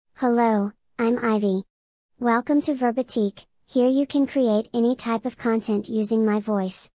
IvyFemale US English AI voice
Ivy is a female AI voice for US English.
Voice sample
Listen to Ivy's female US English voice.
Female